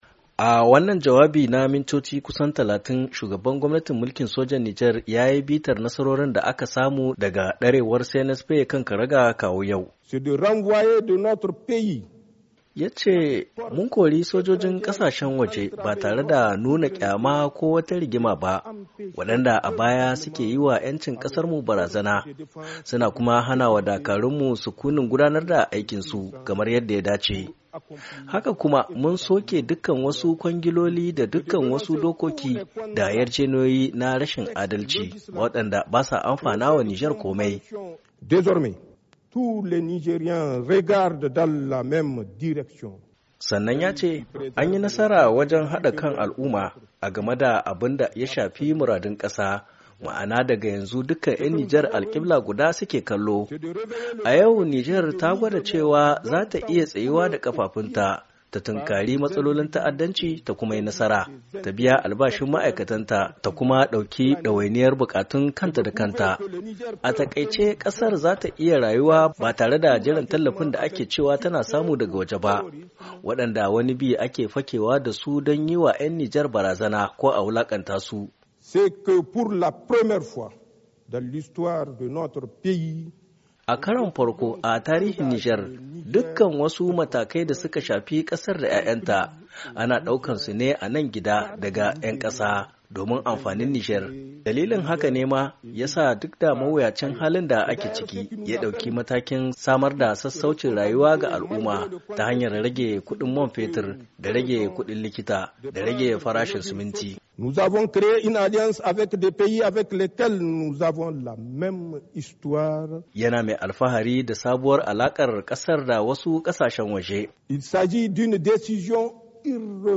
Shugaban gwamnatin mulkin sojan Jamhuryar Nijar Janar Abdourahamane Tiani ya yi jawabi ga al’umma ranar bukin samun 'yancin kai inda ya tabo mahimman batutuwan da suka shafi tafiyar kasar daga lokacin da ya kwaci madafun iko zuwa yau.